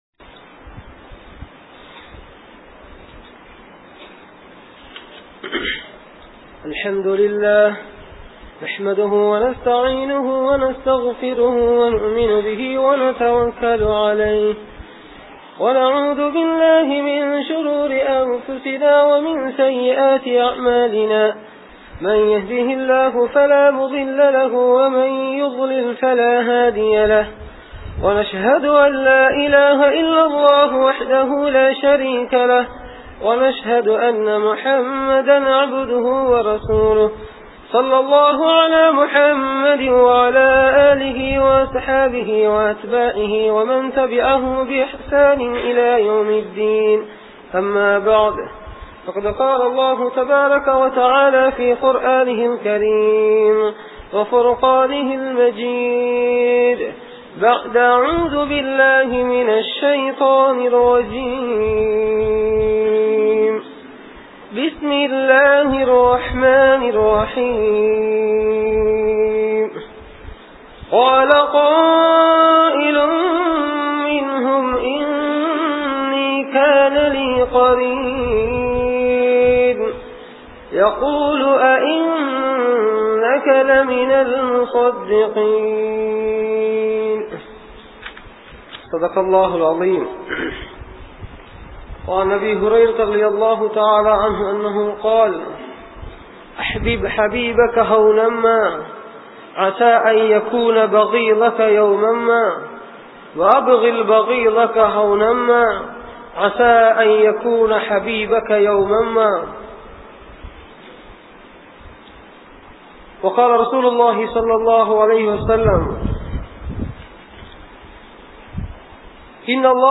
Friendship(நட்பு) | Audio Bayans | All Ceylon Muslim Youth Community | Addalaichenai